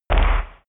Gemafreie Sounds: Impacts